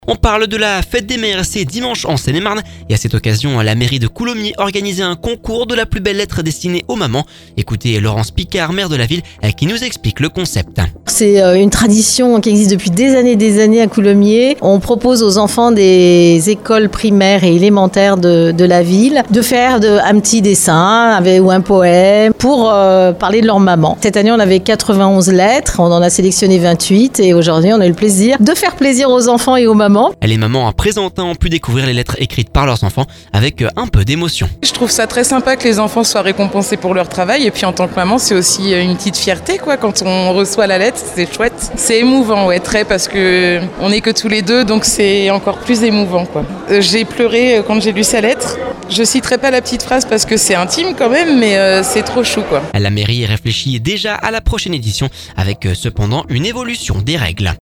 A cette occasion, la mairie de Coulommiers organisait un concours de la plus belle lettre destinée aux mamans. Laurence Picard, maire de la ville nous explique le concept.